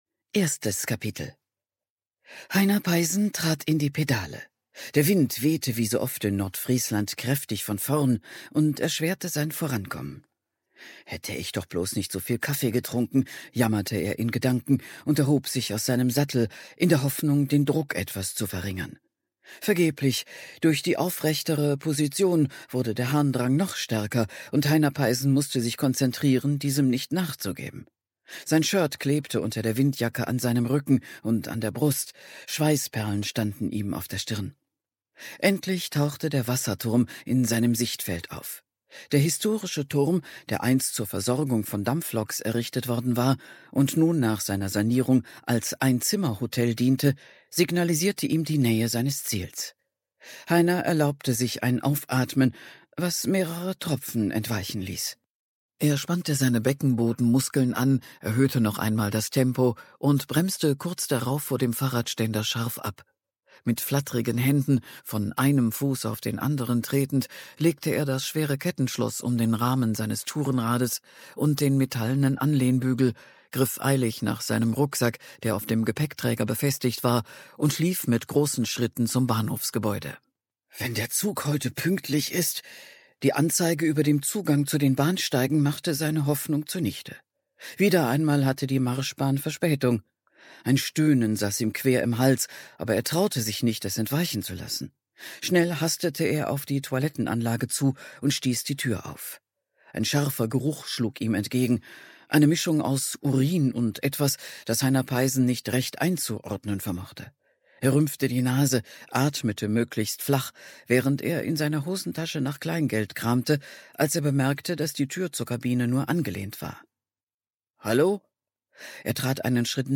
Genre: Krimi & Thriller
Produktionsart: ungekürzt